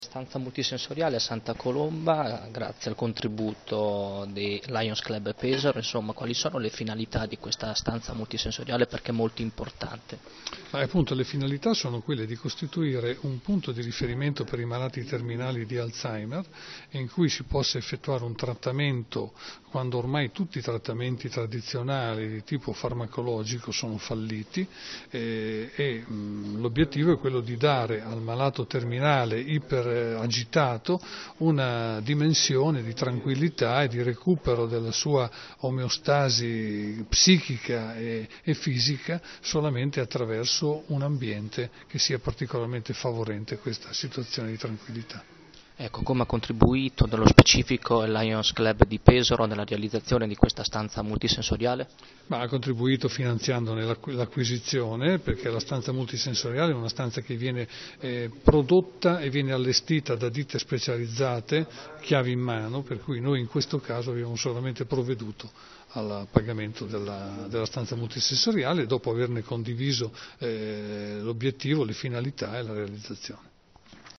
10/04/2013   Conferenza a Santa Colomba Interviste